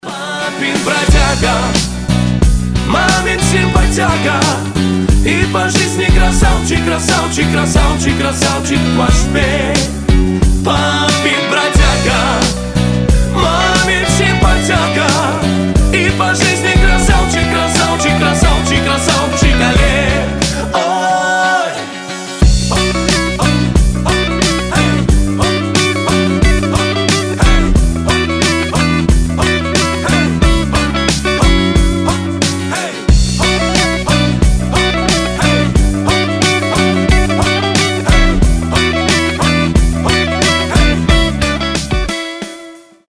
Танцевальные рингтоны
Веселые рингтоны
Поп